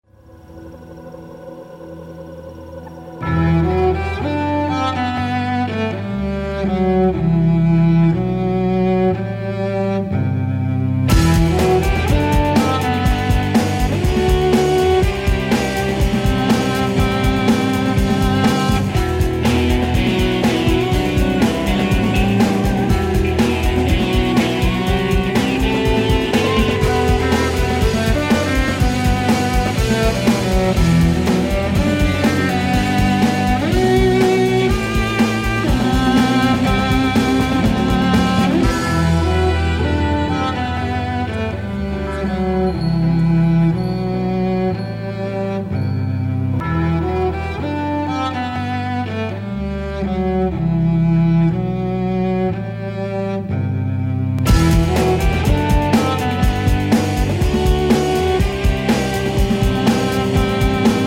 Spike qui se moque d'Angel sur le générique de la série.